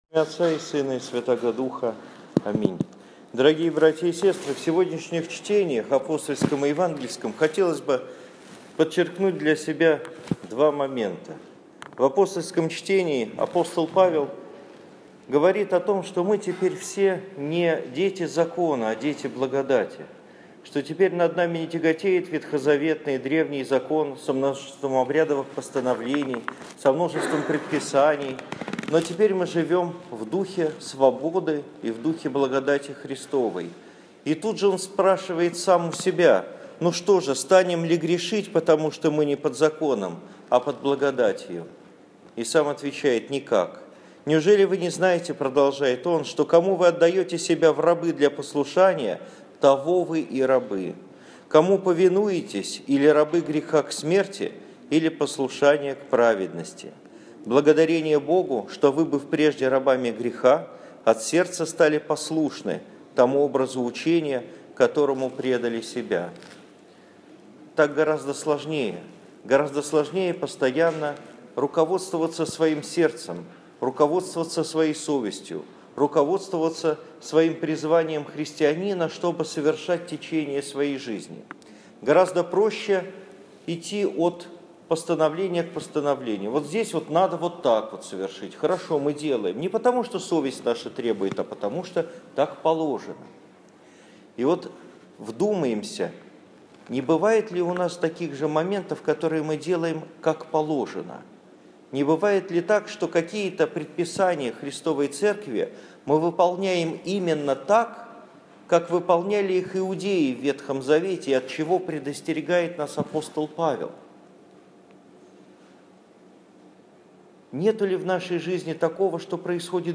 проповедь на субботней литургии 27 июня 2015 года в храме святых апостолов Петра и Павла в Парголово